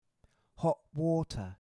hot water – /hɒʔwɔːtə/